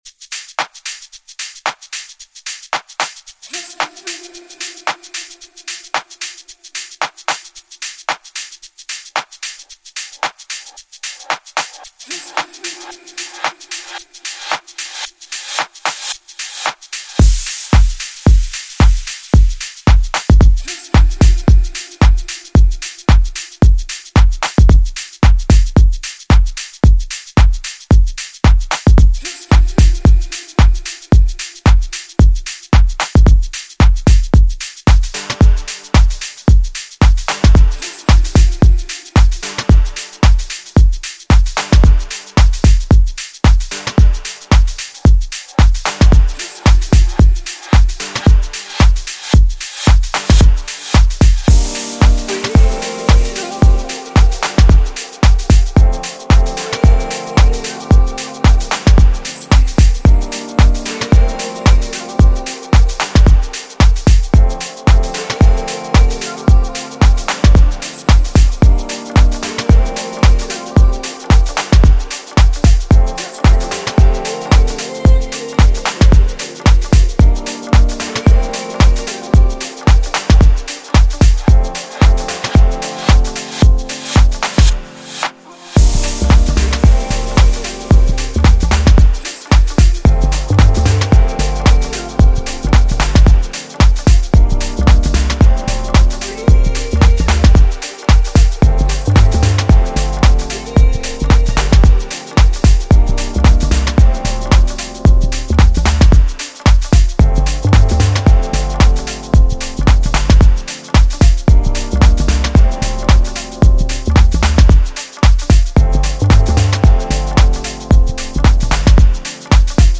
amazing chords and electro house touches